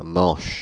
Ääntäminen
Ääntäminen Paris: IPA: [mɑ̃ʃ] Haettu sana löytyi näillä lähdekielillä: ranska Käännöksiä ei löytynyt valitulle kohdekielelle.